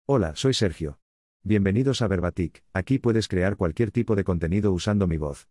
MaleCastilian Spanish
SergioMale Castilian Spanish AI voice
Sergio is a male AI voice for Castilian Spanish.
Voice sample
Listen to Sergio's male Castilian Spanish voice.
Sergio delivers clear pronunciation with authentic Castilian Spanish intonation, making your content sound professionally produced.